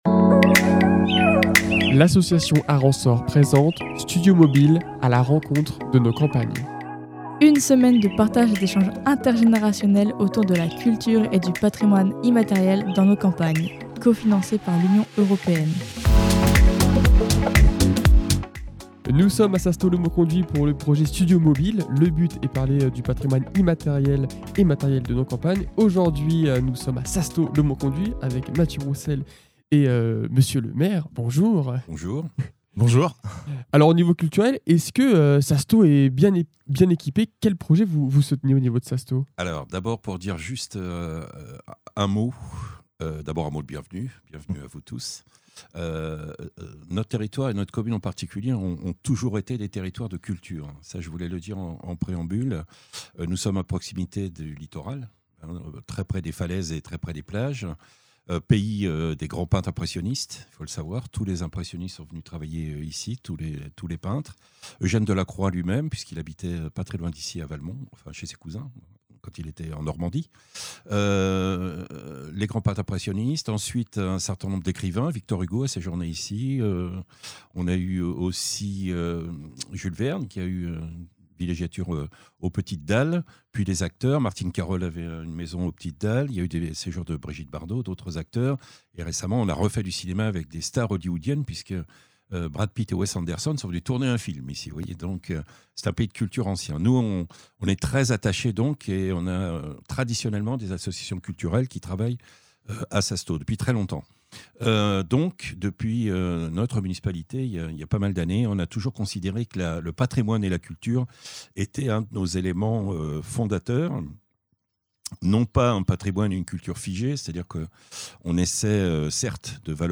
Le maire de Sassetot-le-Mauconduit est venu au micro de Radar pour nous parler du vivier artistique important du village, que ce soit au passé, au présent ou au futur !